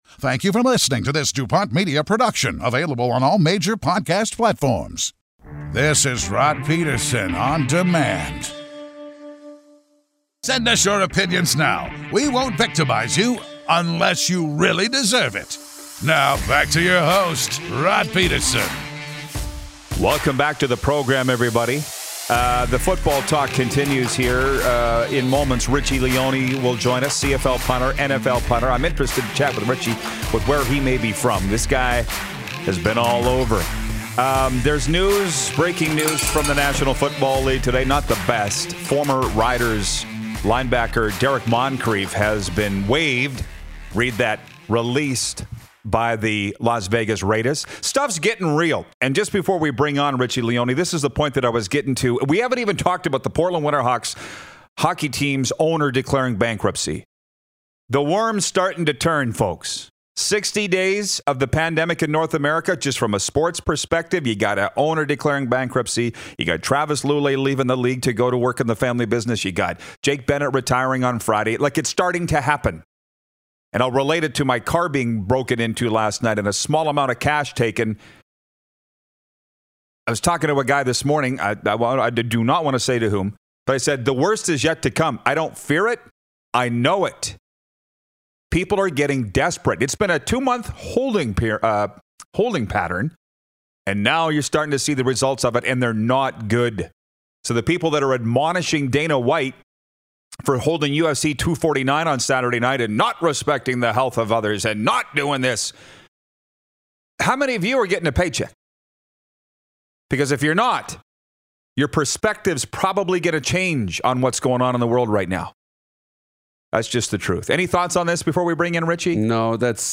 Going LIVE on a Monday with a solid lineup!